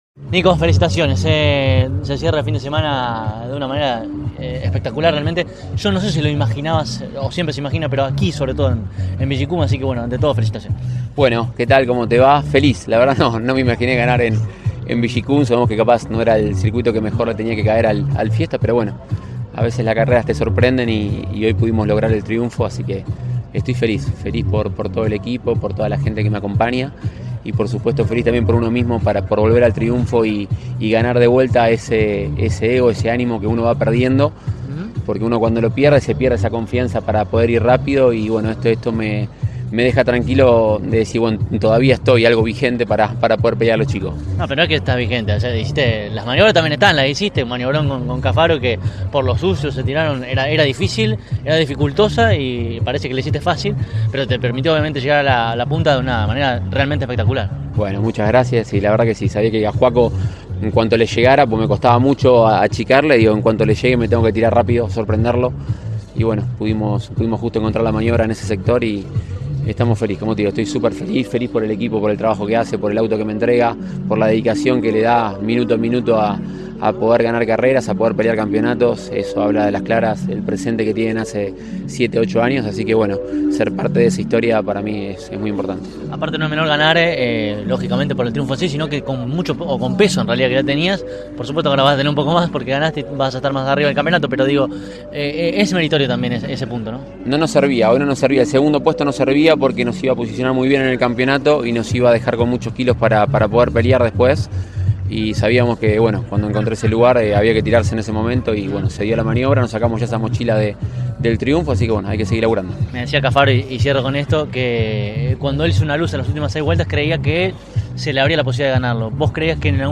El TN disputó, este fin de semana, su cuarta cita de la presente temporada y, en el caso puntual de la divisional menor, una vez culminada la prueba final, cada uno de los integrantes del podio dialogó con CÓRDOBA COMPETICIÓN.